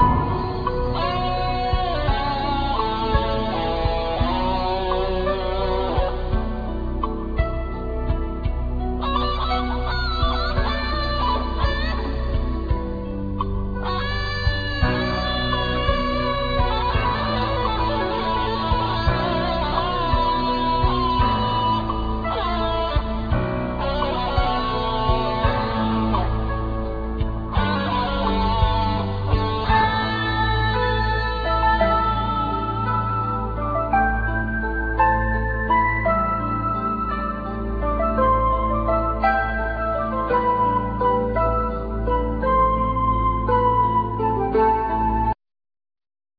Guitar(Spanish,Acoustic),Banjo,Percussions
Synthsizer,Programming
Guitar(Spanish,Electric),Percussions
Tenor saxophone